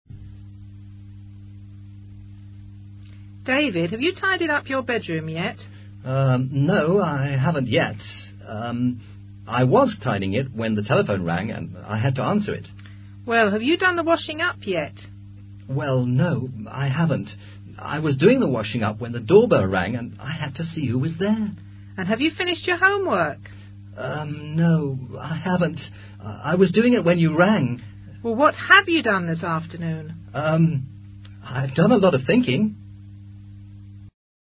Conversación entre un padre y su hijo. El primero intenta averiguar si el segundo ha hecho sus deberes.